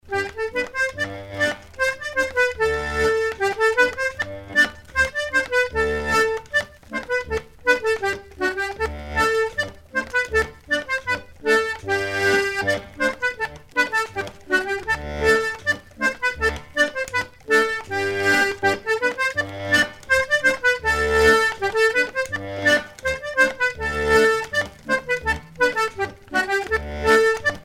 danse : scottich trois pas ; danse : sicilienne ;
Pièce musicale éditée